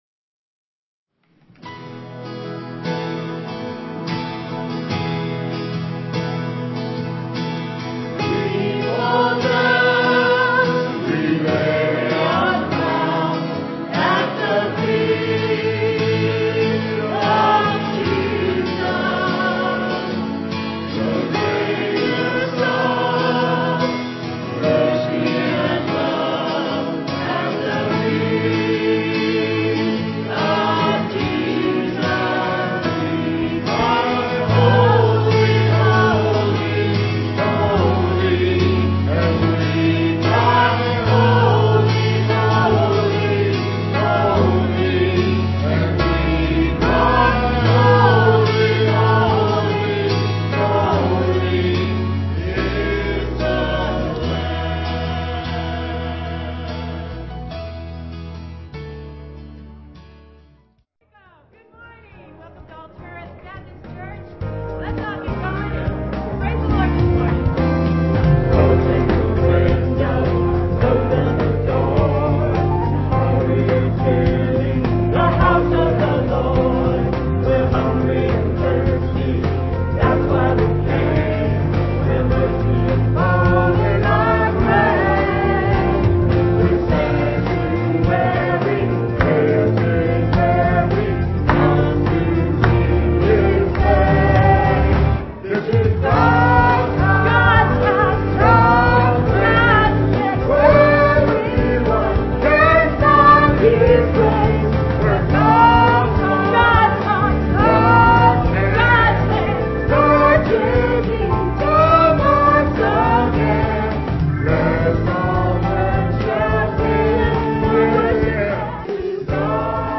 Offertory
piano
digital piano.